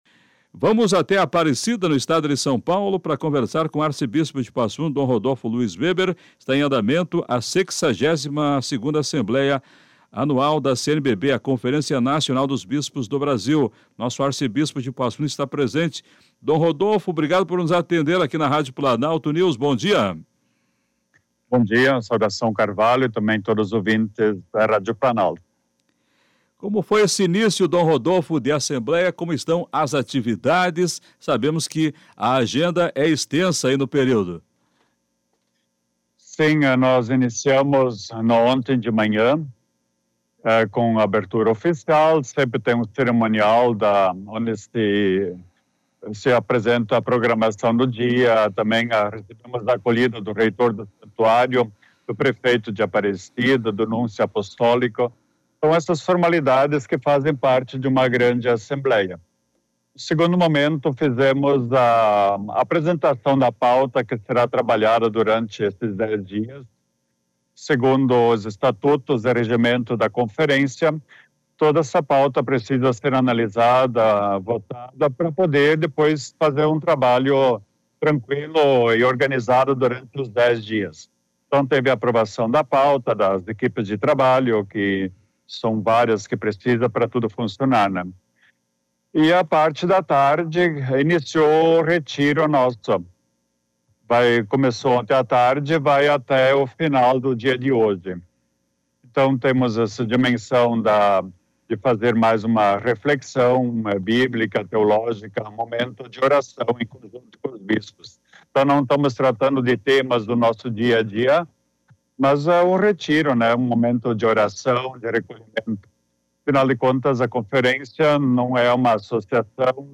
Assembleia Geral da CNBB: arcebispo dom Rodolfo Luís Weber fala direto de Aparecida à Planalto News
O arcebispo de Passo Fundo, dom Rodolfo Luís Weber, participa da Assembleia.